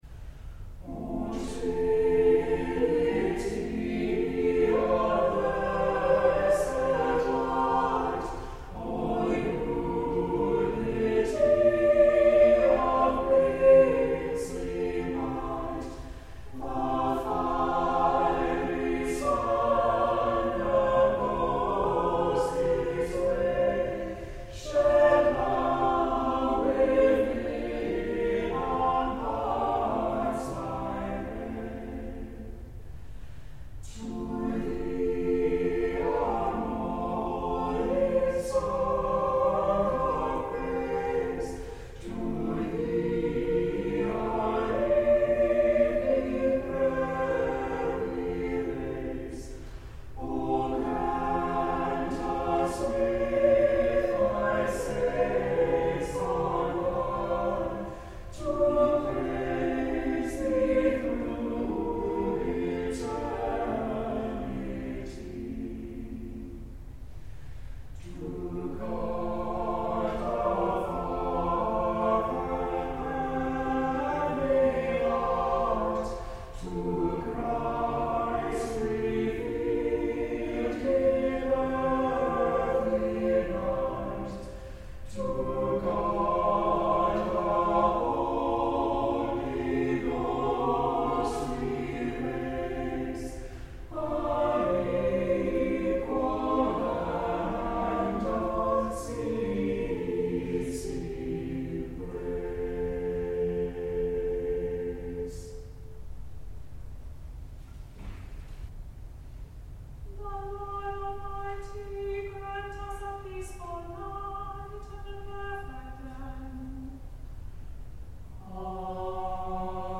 Worship and Sermons from Christ Episcopal Church in Little Rock, Arkansas
On Sundays, virtual and in-person services of prayers, scripture, and a sermon are at 8 a.m., 10:30 a.m., and 6 p.m., and a sung service of Compline begins at 7 p.m.